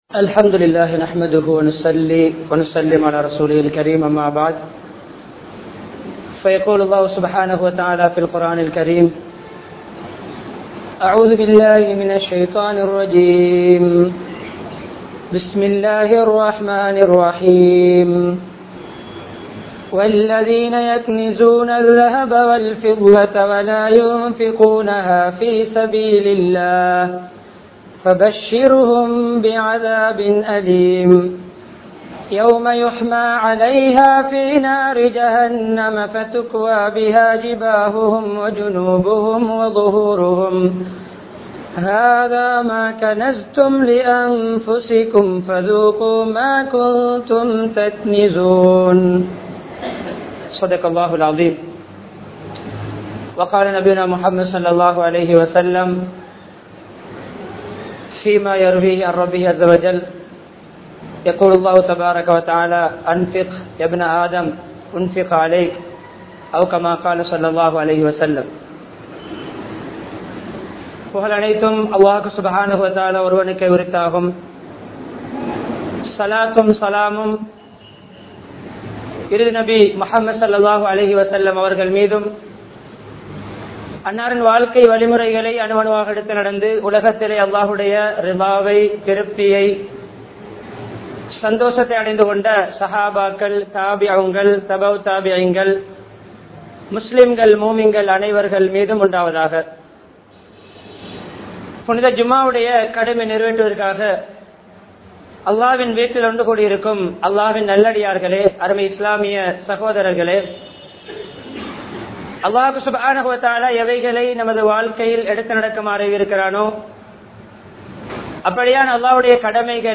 Islaththin Paarvaiel Ealmaium Selvamum (இஸ்லாத்தின் பார்வையில் ஏழ்மையும் செல்வமும்) | Audio Bayans | All Ceylon Muslim Youth Community | Addalaichenai
Colombo 14, Layards Broadway, Jamiul Falah Jumua Masjidh